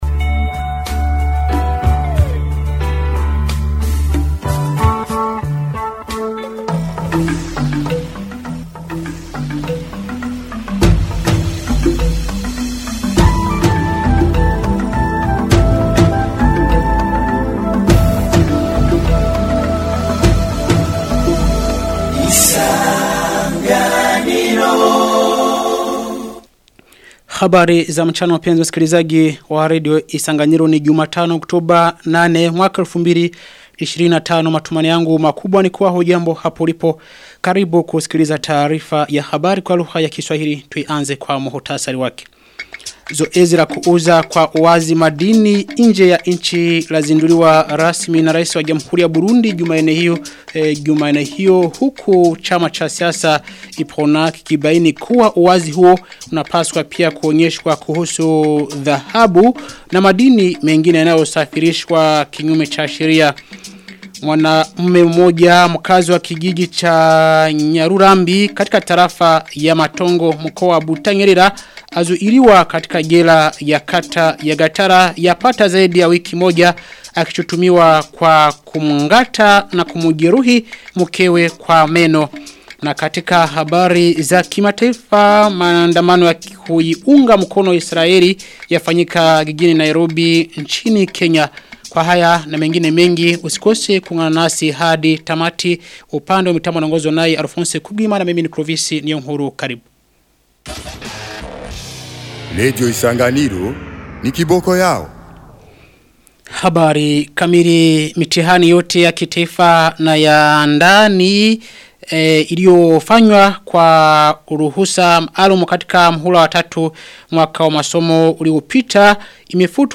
Taarifa ya habari ya tarehe 8 Oktoba 2025